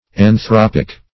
Anthropic \An*throp"ic\, Anthropical \An*throp"ic*al\, a. [Gr.